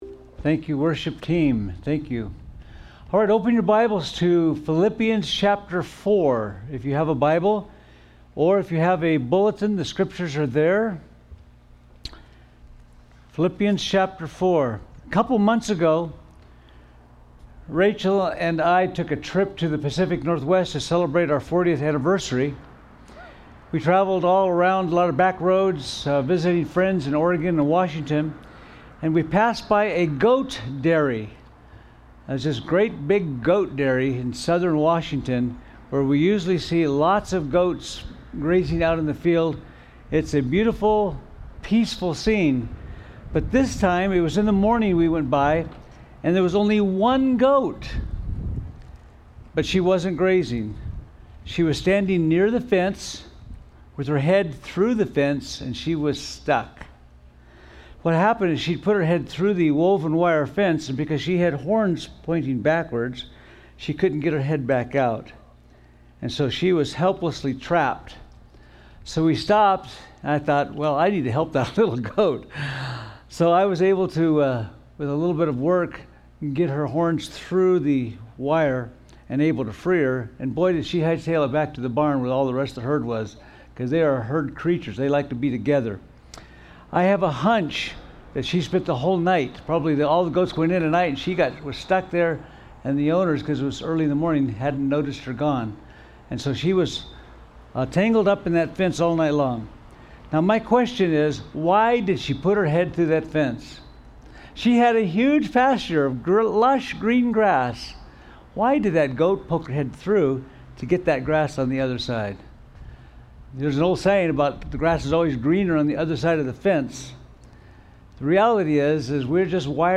Sermons
Related Topics: Drive-In Service